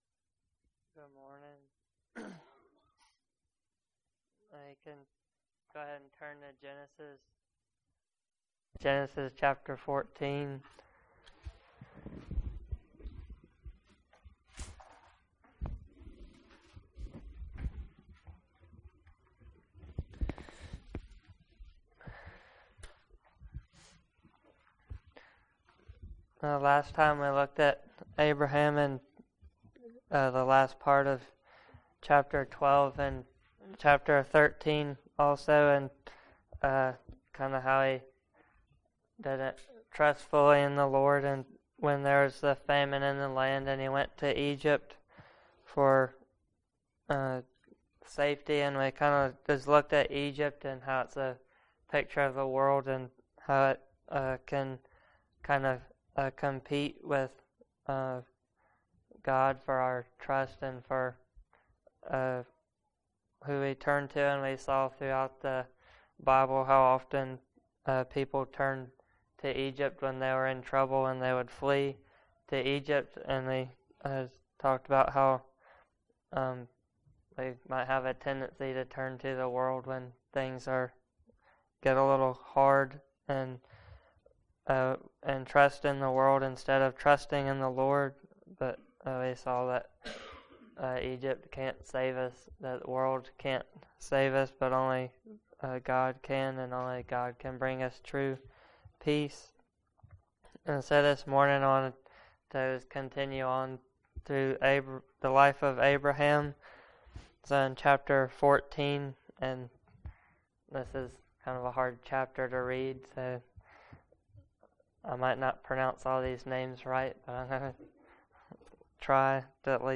Passage: Genesis 14 Service Type: Sunday Morning Related « The Humility of Love What is the Biblical basis for Pre-Tribulation Rapture?